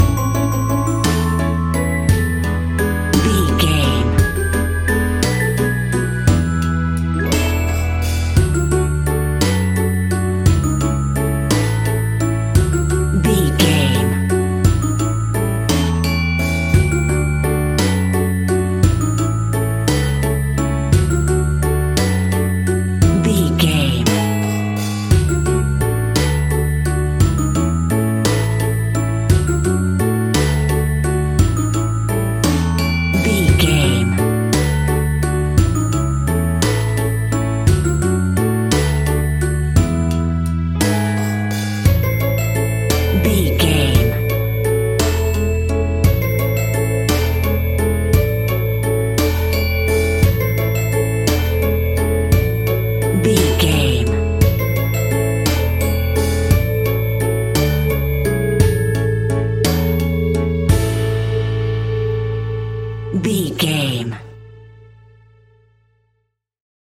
Ionian/Major
childrens music
instrumentals
fun
childlike
cute
happy
kids piano